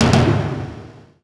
quest_receive.wav